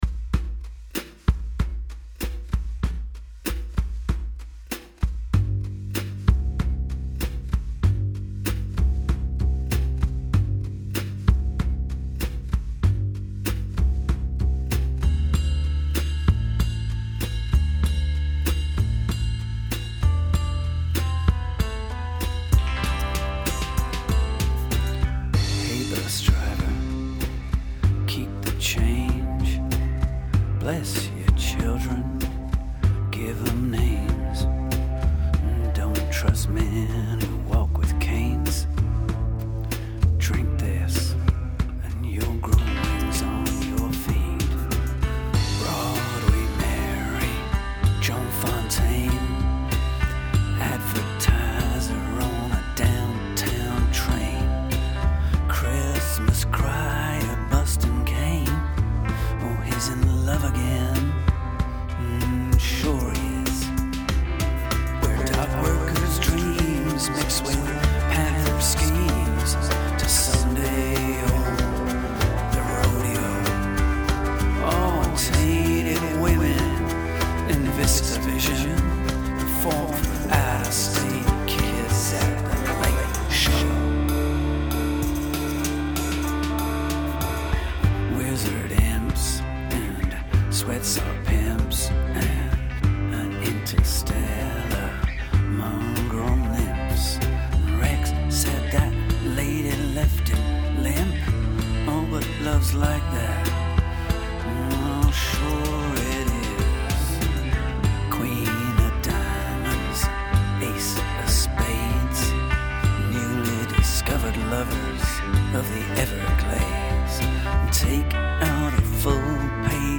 a slapback rockabilly chorus